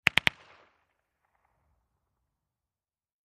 Mac-10 Machine Gun Burst From Distant Point of View, X2